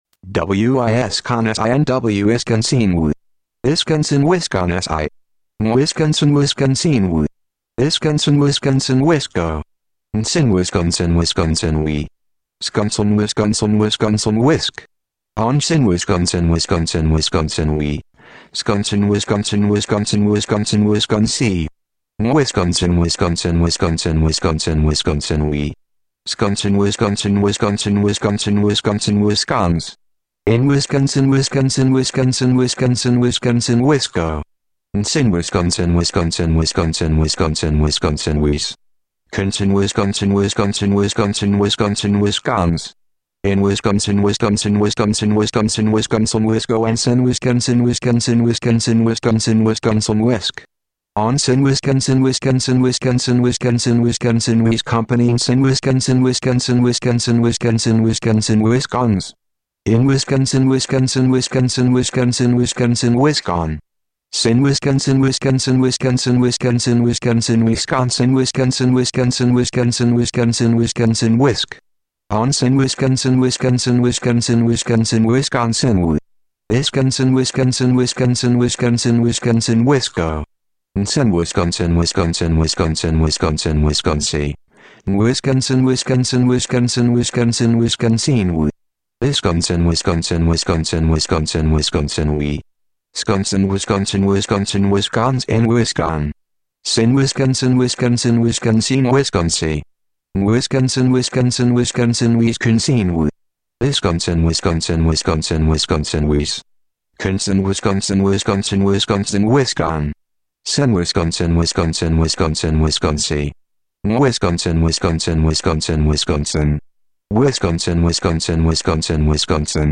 Dramatic reading